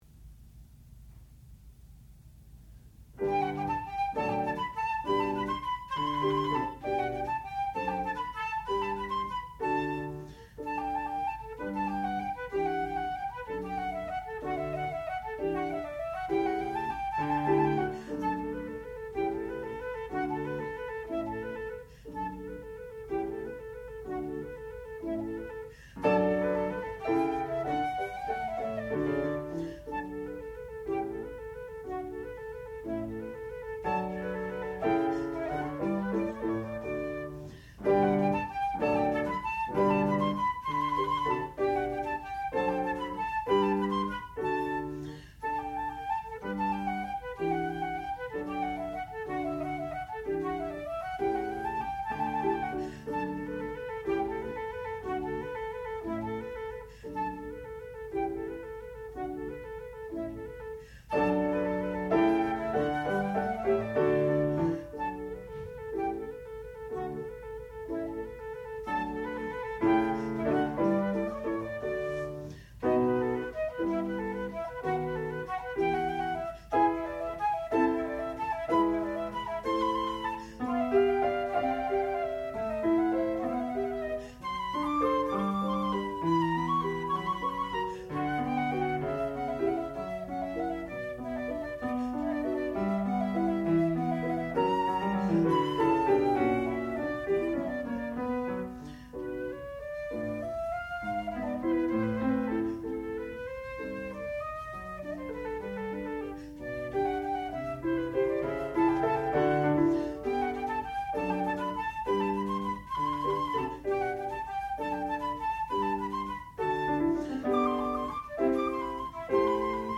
sound recording-musical
classical music
flute
harpsichord
Master's Recital